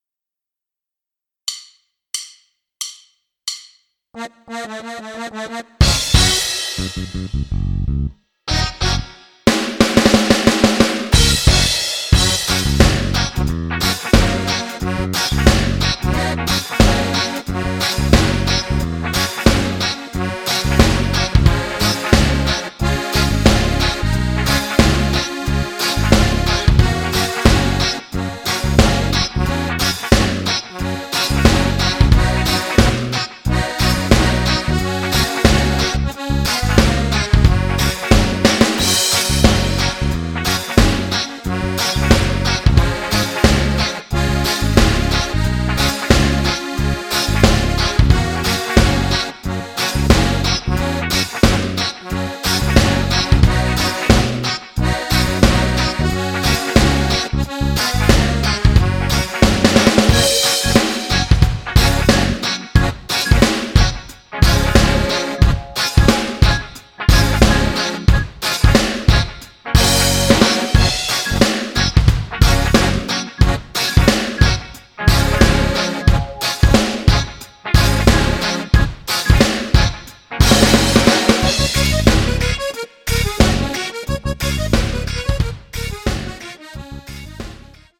MP3 Karaoke, Instrumental